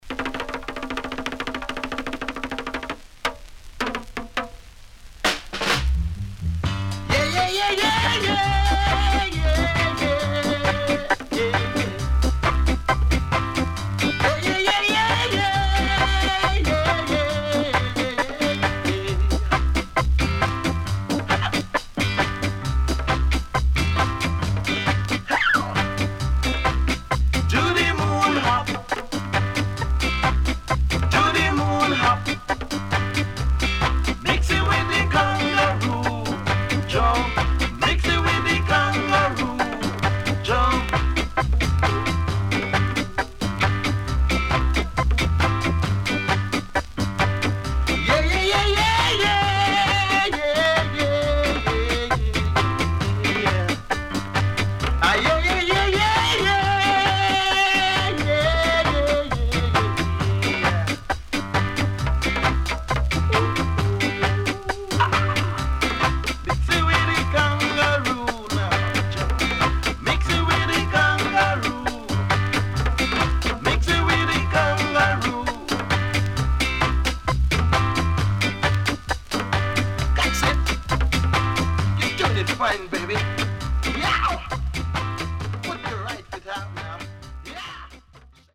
Good Early Reggae Compilation Album
SIDE A:全体的にプレス起因のノイズ入ります。